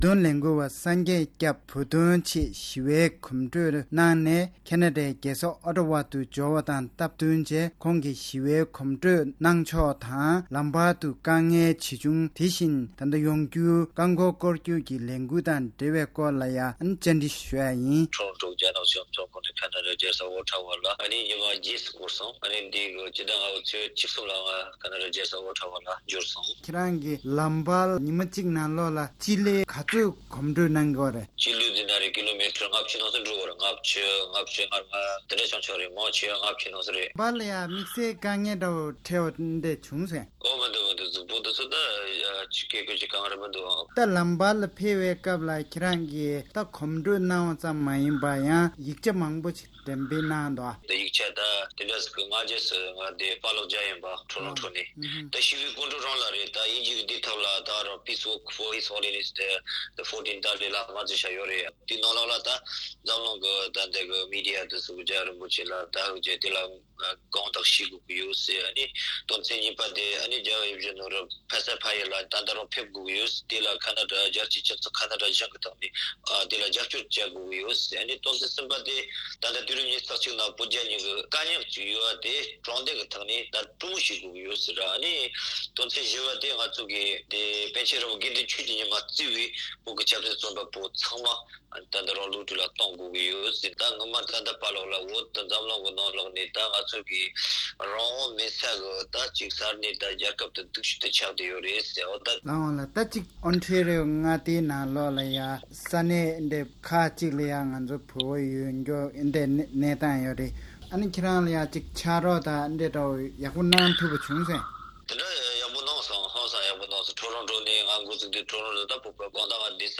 ཁོང་བཅར་འདྲི་ཞུས་པར་གསན་རོགས་ཞུ།།